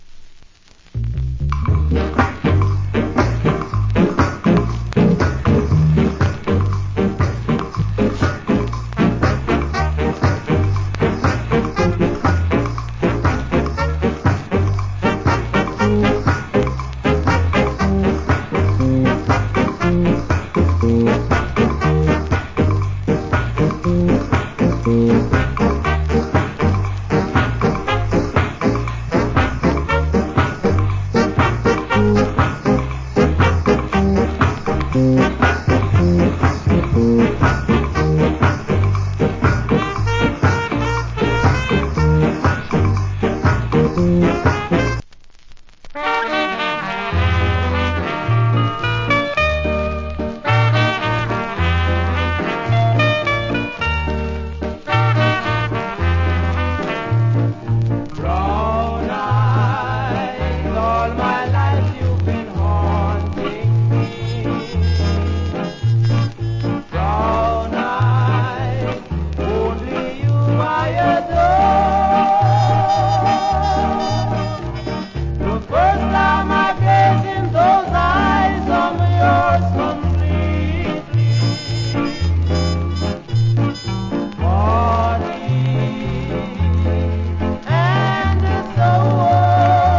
Condition VG(OK,HISS,WOL)
コメント Nice Ska Inst. / Good Vocal.